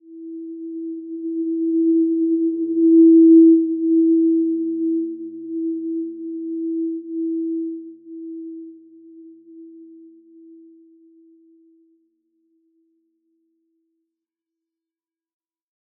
Simple-Glow-E4-p.wav